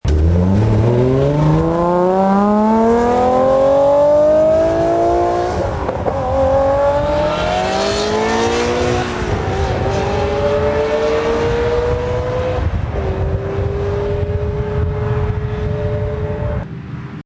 F50 and F355 take off (172K MP3 format) A Quick clip that we recorded at Texas World Speedway of an F50 and an F355 taking off down the pits. new
F50_f355takeoff.mp3